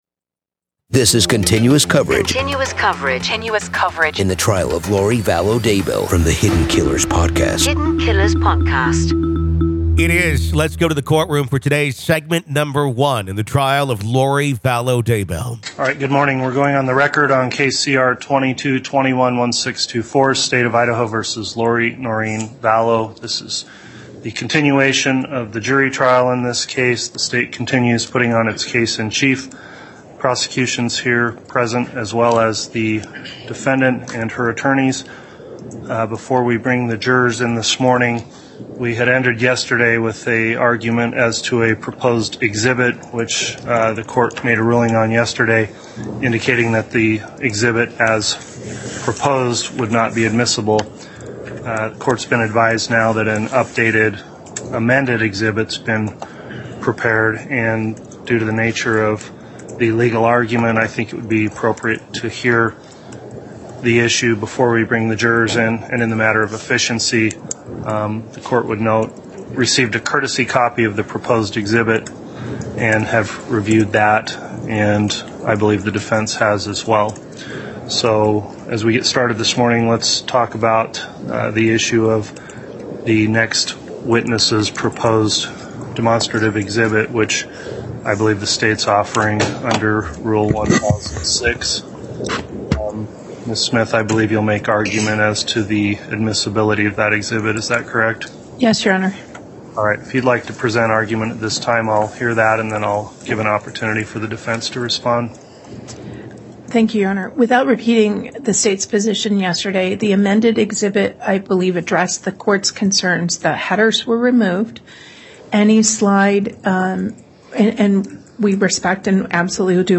In this true crime series, we examine the disappearance and deaths of Lori's children, Tylee Ryan and JJ Vallow, and the charges against Lori and her husband, Chad Daybell. With raw courtroom audio, and interviews from experts and insiders, we analyze the evidence and explore the strange religious beliefs that may have played a role in this tragic case.